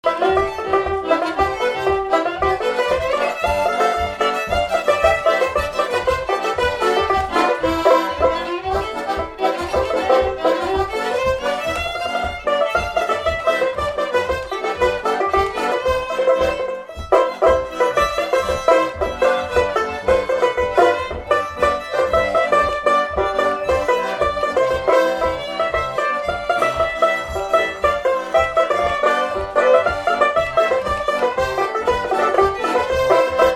Tampon (Le)
Instrumental
danse : séga
Pièce musicale inédite